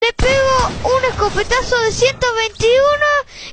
Play the 5 Escopetazo De 121 sound button instantly. 4-second meme soundboard clip — free, in-browser, no signup, no download required.
A Spanish language sound referencing a shotgun blast, likely from a game or viral video.